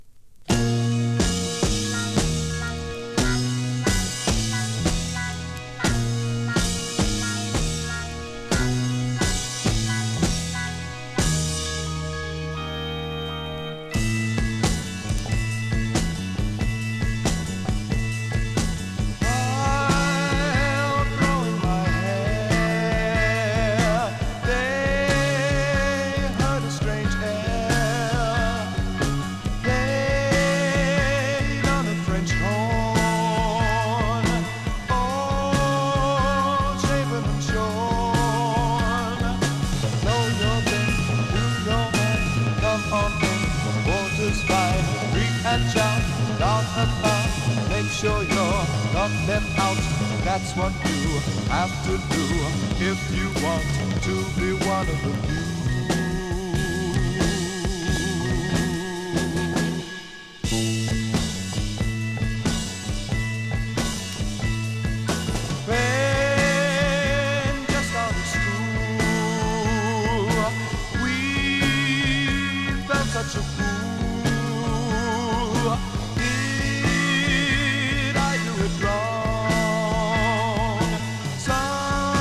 Remastered from the original tapes